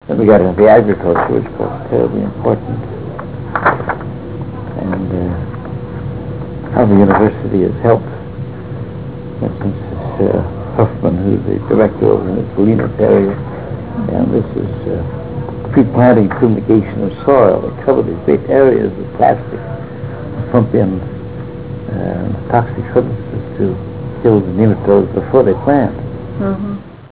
217Kb Ulaw Soundfile Hear Ansel Adams discuss this photo: [217Kb Ulaw Soundfile]